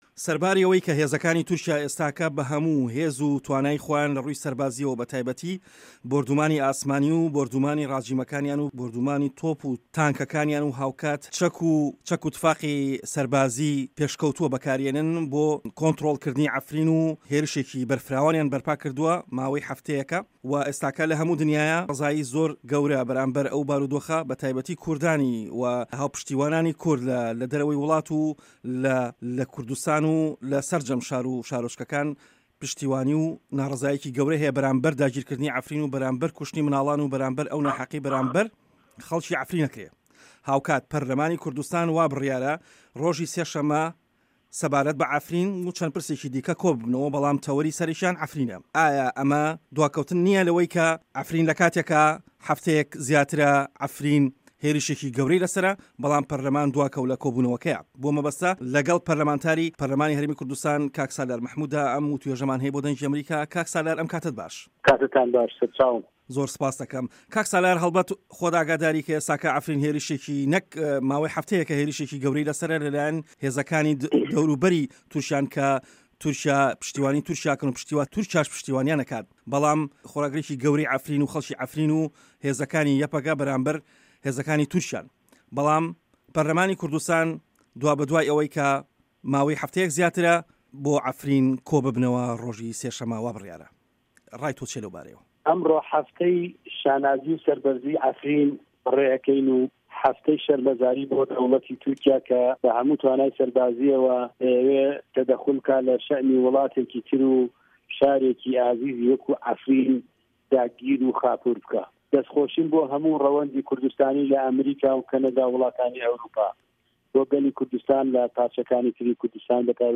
وتووێژ لەگەڵ سالار مەحمود